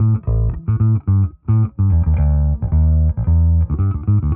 Index of /musicradar/dusty-funk-samples/Bass/110bpm
DF_PegBass_110-A.wav